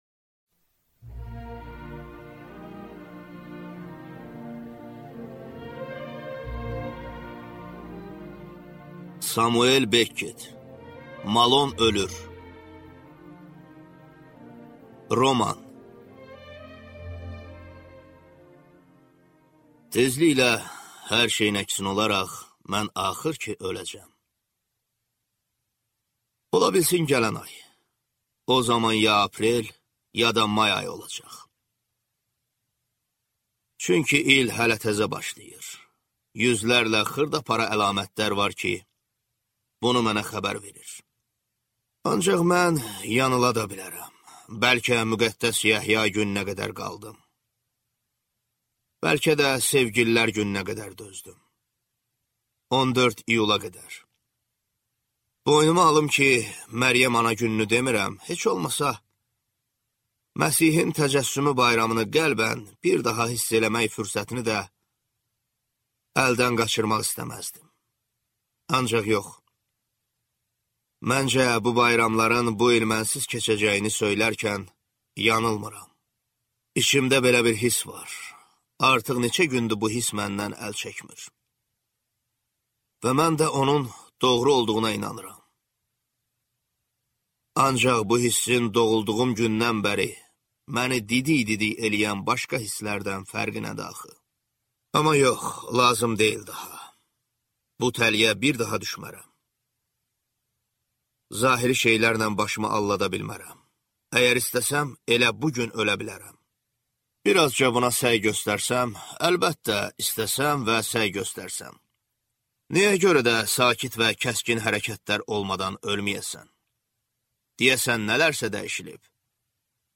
Аудиокнига Malon ölür | Библиотека аудиокниг
Прослушать и бесплатно скачать фрагмент аудиокниги